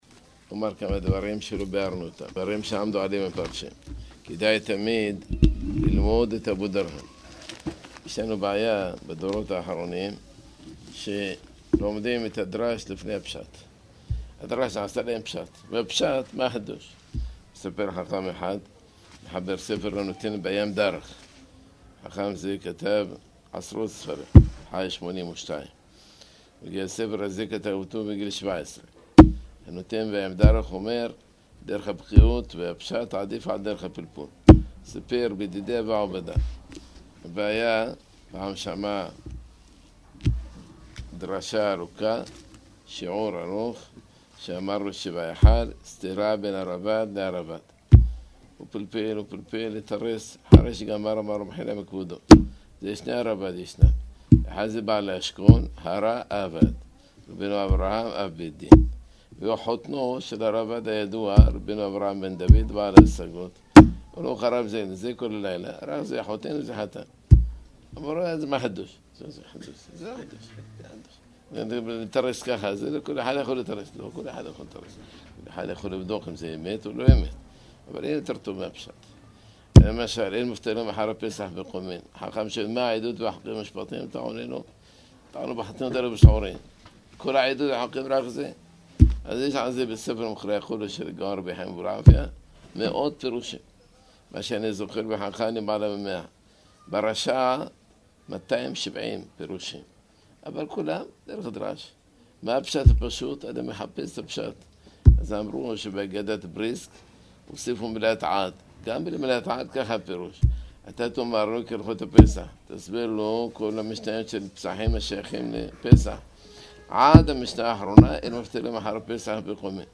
שיעור קצר שנמסר בין מנחה לערבית, בענייני – ההגדה של פסח חלק א – תש''ע